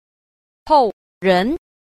9. 後人 – hòu rén – hậu nhân (người đời sau)